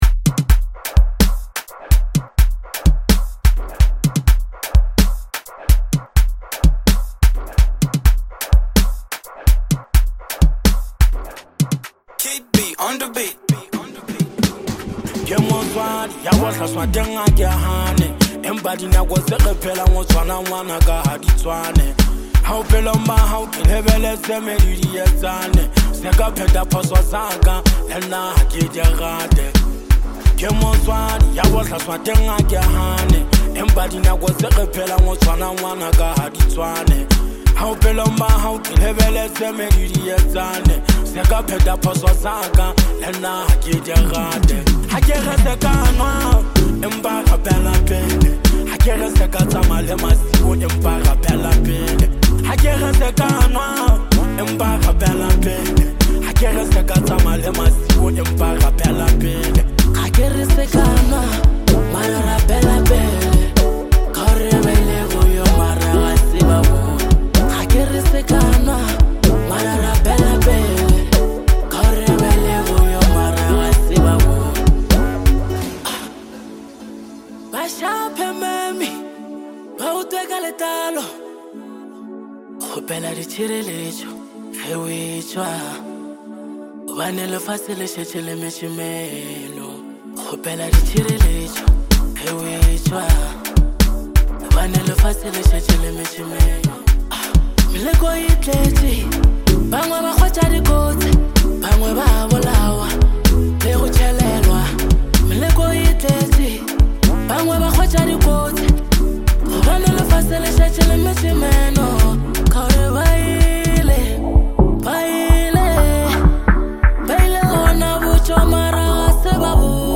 spiritually uplifting track